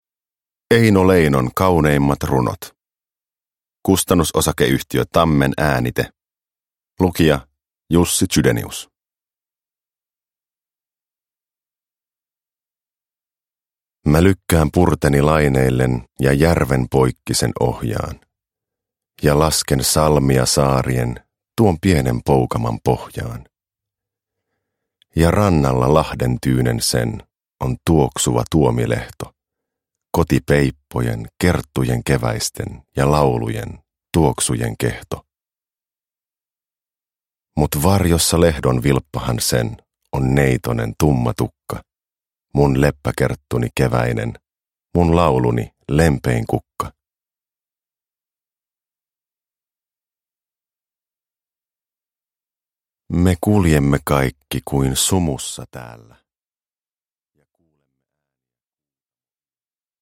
Lukijana Rajaton-yhtyeestä tuttu Jussi Chydenius.
Uppläsare: Jussi Chydenius